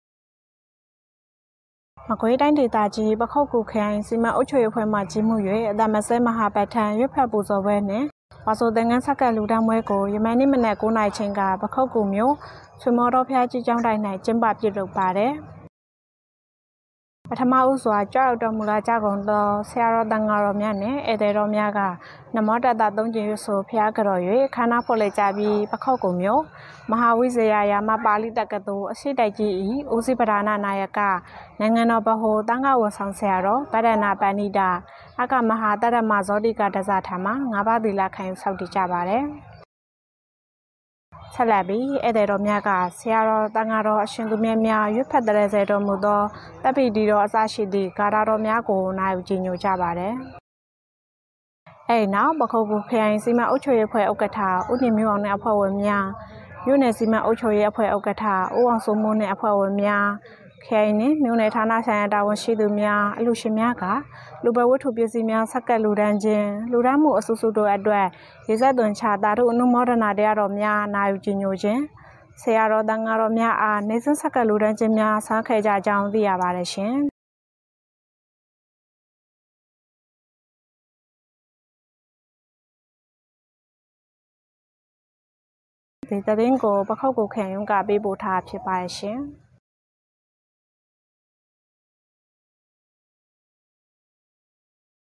ပခုက္ကူမြို့၌ အသံမစဲမဟာပဋ္ဌာန်းရွတ်ဖတ်ပူဇော်ပွဲနှင့်ဝါဆိုသင်္ကန်းဆက်ကပ်လှူဒါန်းပွဲကျင်းပ